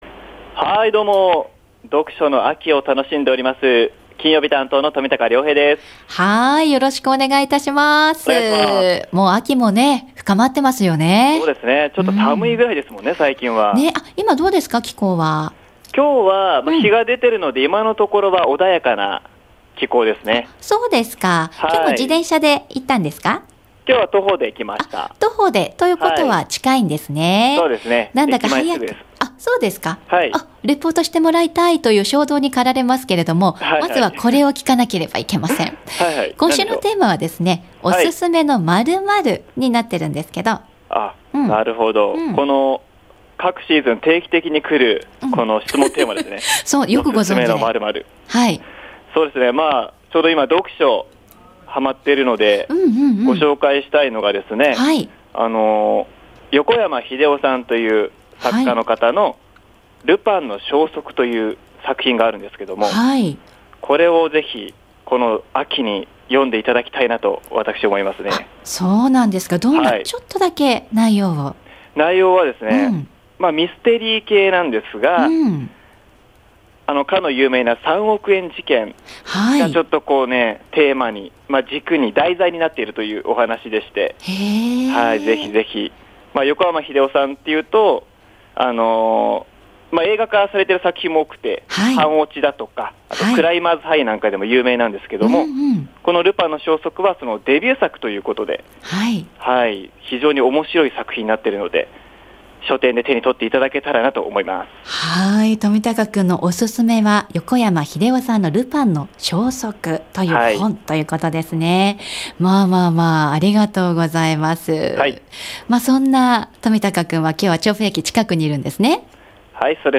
午後のカフェテラス 街角レポート